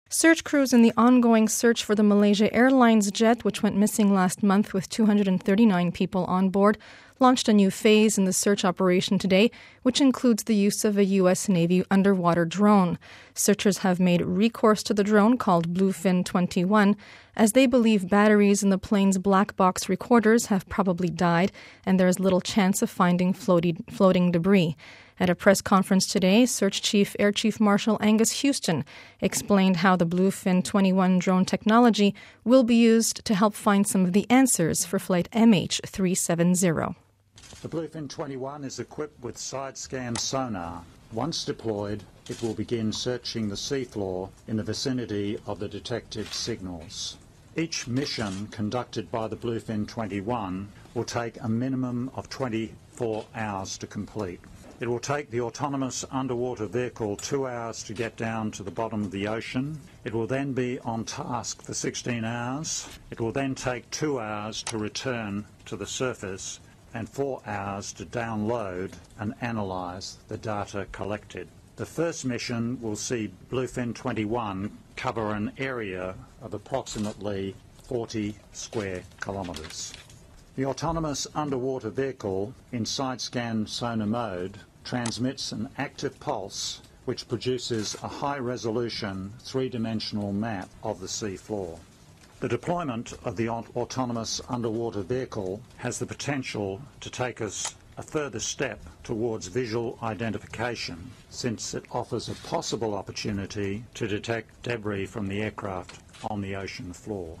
At a press conference, search chief Air Chief Marshal Angus Houston explained how the Bluefin-21 drone technology will be used to help find some of the answers for flight MH370.